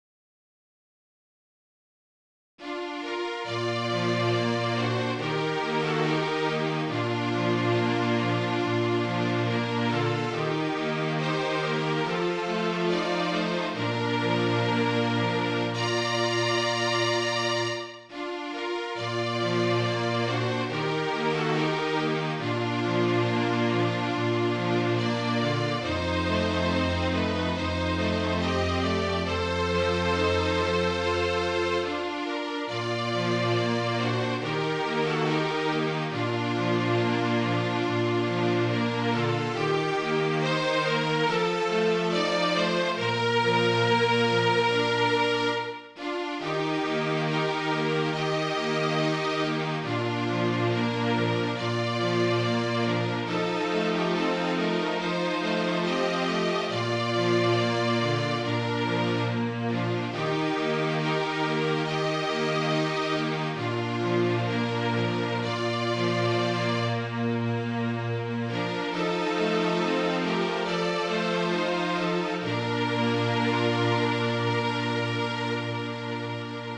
川崎・大田区 弦楽合奏団 フルートアンサンブル シニア合奏団 多摩川ストリングス 芝田祥子ヴァイオリン教室